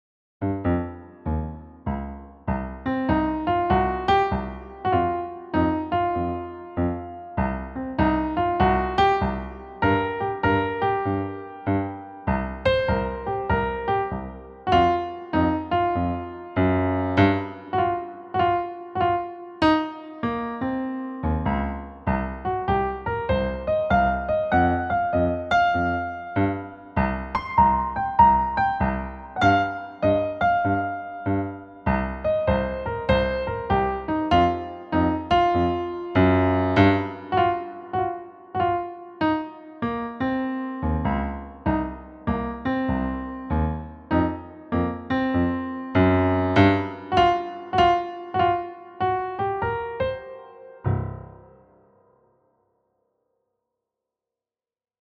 a sassy, swing-inspired blues solo
Key: C Blues scale
Time Signature: 4/4 (Relaxed Swing, BPM ≈ 98)